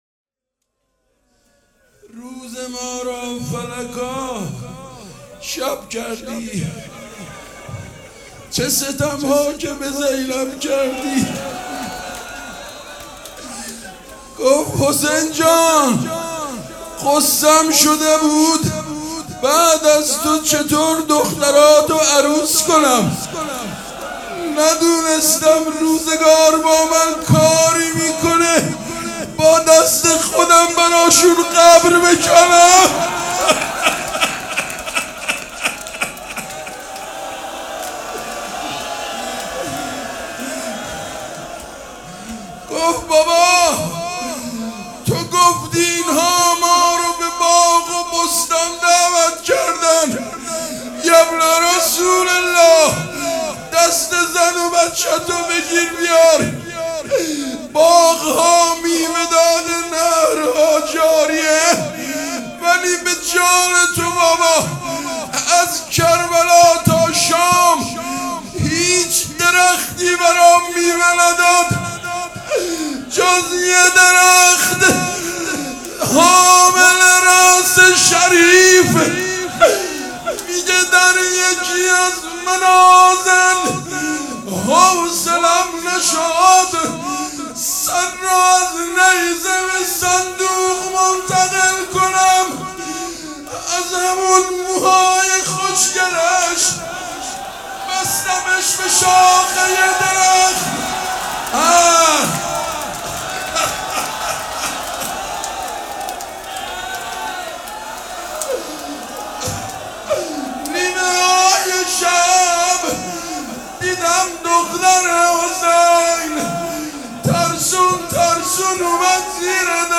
روضه بخش سوم
روضه شب سوم مراسم عزاداری صفر